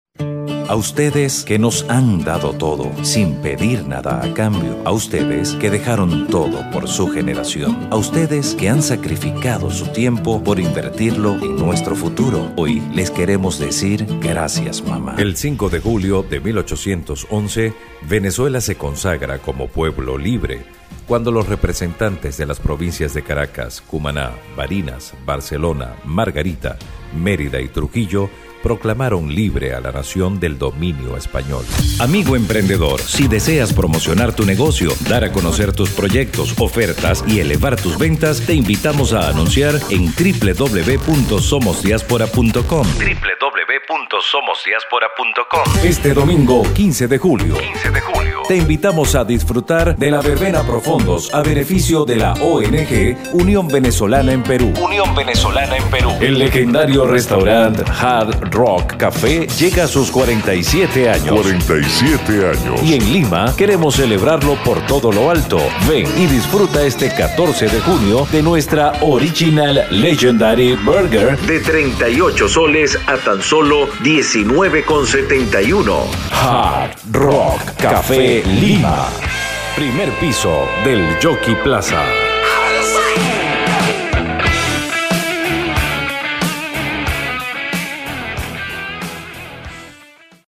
Profesional Venezolano, Comunicador Social, con experiencia en Radio y Televisión, Voz Marca y Narración de Noticias.
Sprechprobe: Industrie (Muttersprache):